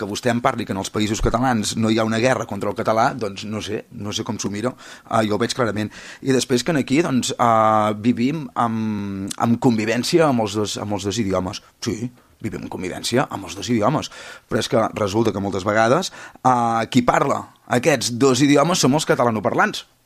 Les seves paraules van ser rebatudes pel portaveu de la CUP, Albert Comas, i l’alcalde de Calella, Marc Buch, que va voler posar-la davant del mirall.
Albert Comas (CUP):